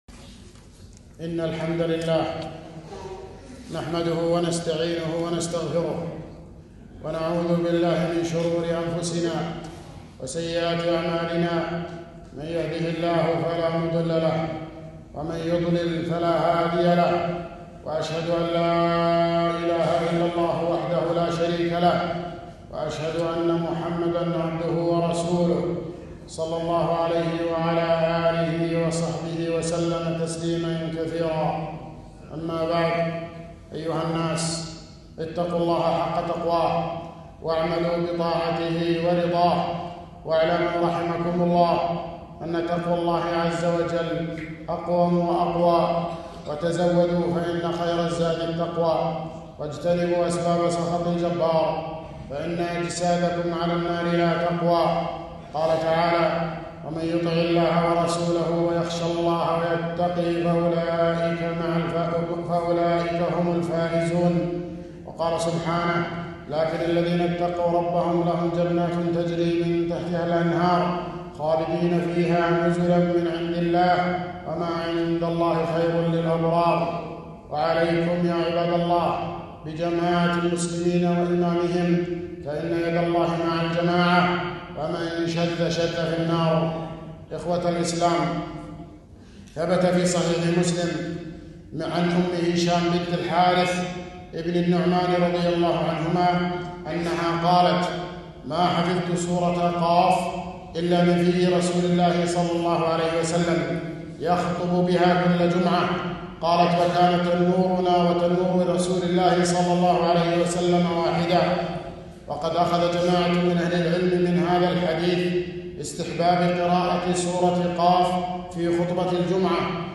خطبة - قراءة سورة (ق)